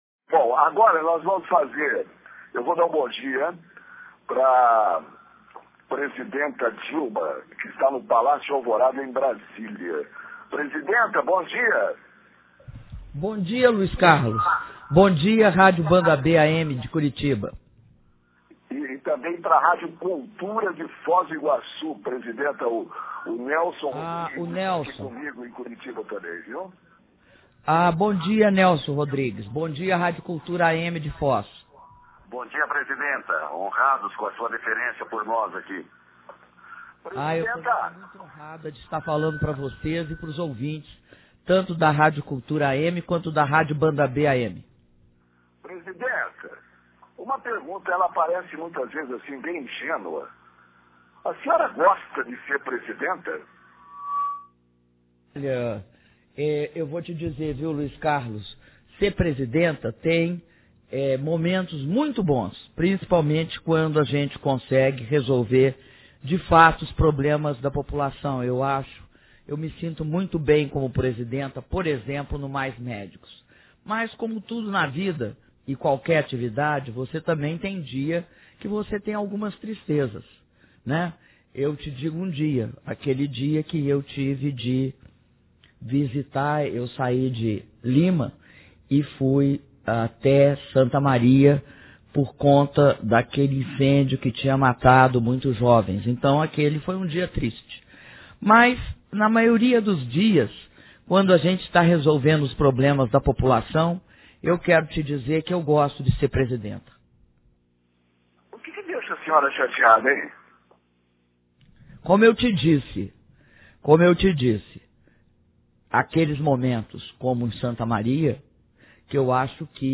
Áudio da entrevista exclusiva concedida pela Presidenta da República, Dilma Rousseff, para as rádios Cultura AM, de Foz do Iguaçu, e Banda B AM, de Curitiba